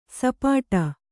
♪ sapāṭa